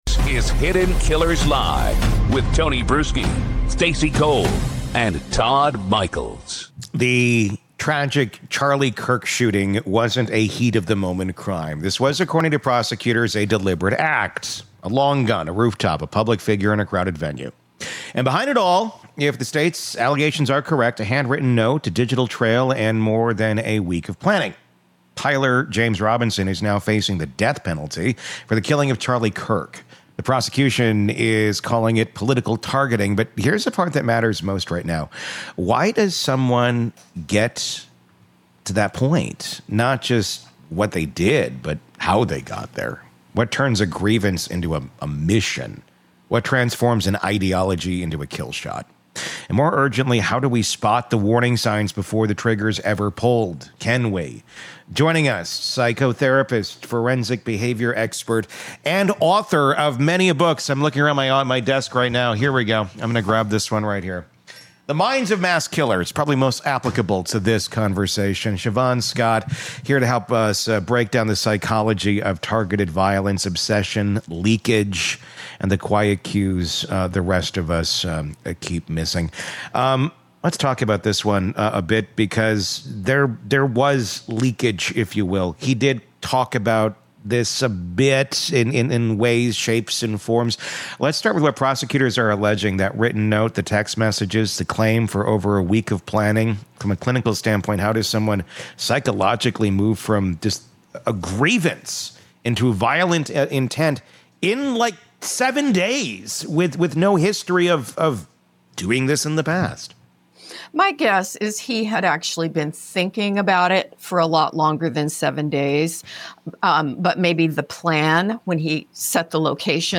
In this interview, we explore: